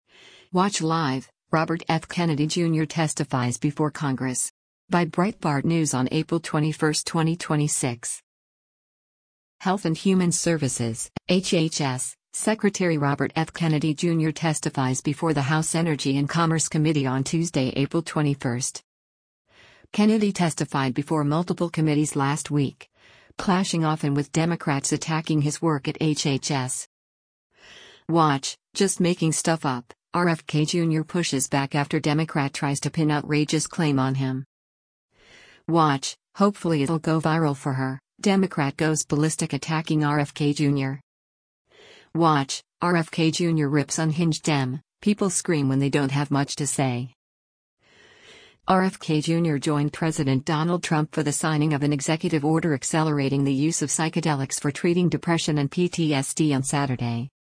Health and Human Services (HHS) Secretary Robert F. Kennedy Jr. testifies before the House Energy and Commerce Committee on Tuesday, April 21.